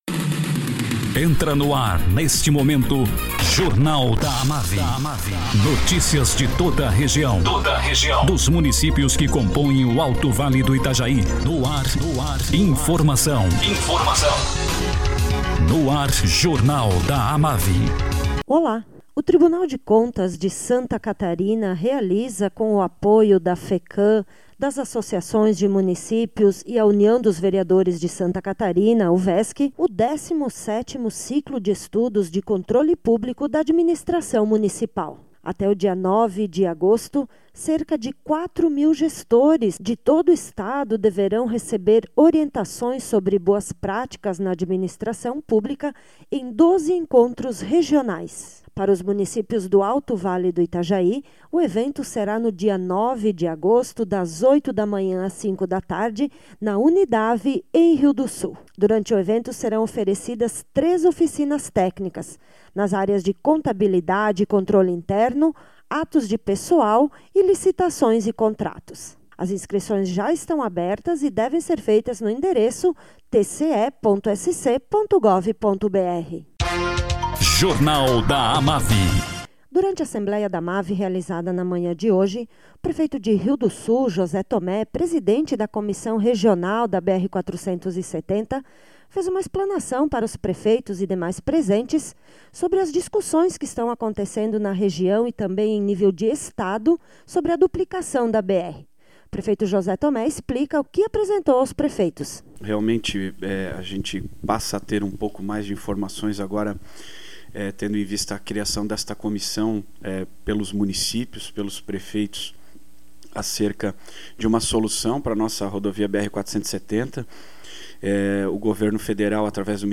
Prefeito de Rio do Sul, José Thomé, presidente da comissão regional em prol da BR-470, fala sobre as discussões que estão sendo realizadas em nível regional e estadual.